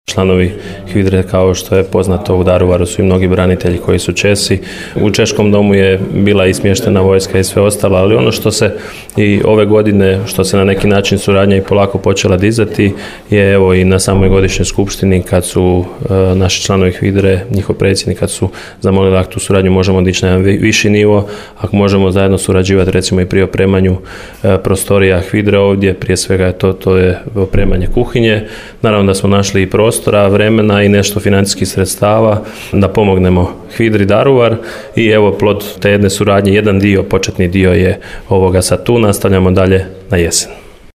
Saborski zastupnik za češku i slovačku nacionalnu manjinu Vladimir Bilek i kandidat za zamjenika gradonačelnika Grada Daruvara podsjetio je na doprinos pripadnika češke nacionalne manjine u Domovinskom ratu ovim riječima: